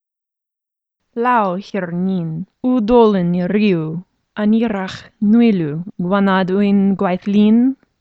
Elvish Line, click on it to hear the line being spoken by a voice actor.
/ˈlaw   ˈhi:r   ˈniˑn   ˌuˑ   ˈdɔl.lɛn   i   ˈri:w/   /a.ˈniˑ.rax   nuj   ˈlu:   ˈgwan.nad   ujŋ   ˈgwajθ   ˈliˑn/